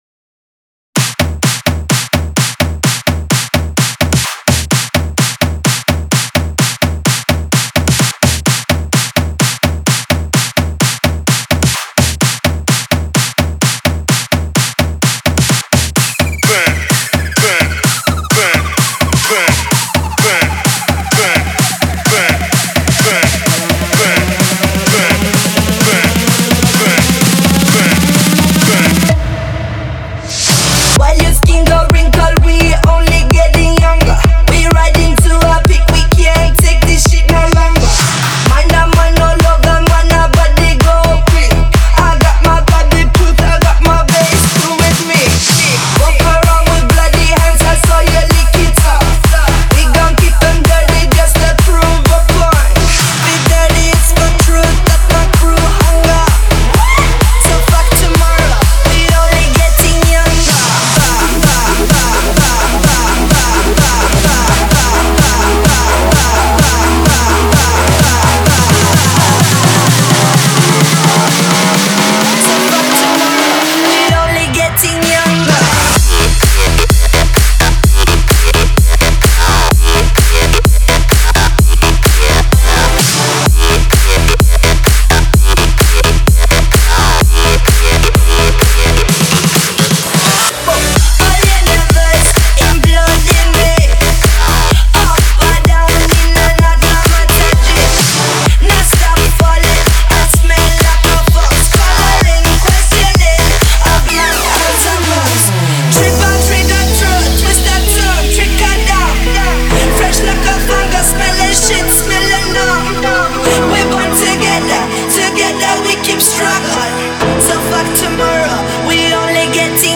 Категория: Ремиксы